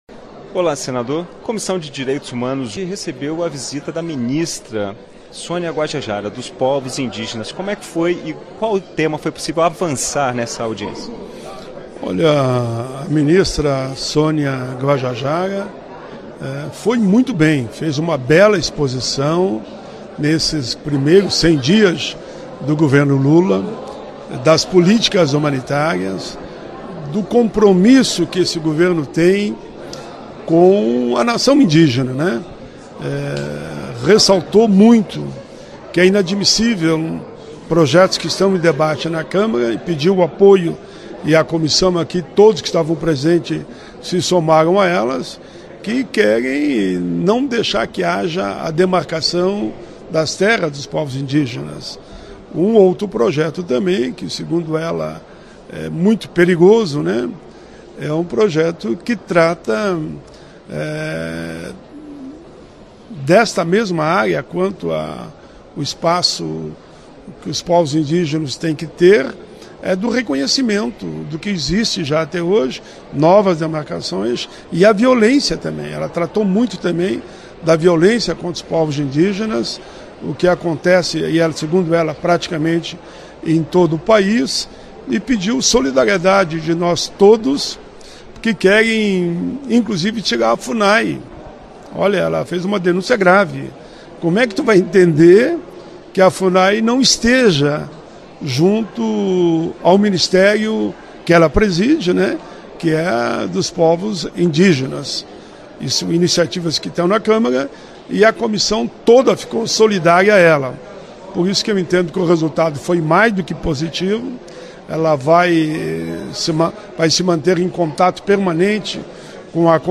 Em entrevista à TV Senado, o senador Paulo Paim (PT-RS) comenta a participação da ministra dos Povos Indígenas, Sonia Guajajara, na Comissão de Direitos Humanos e Legislação Participativa (CDH) na quarta-feira (10).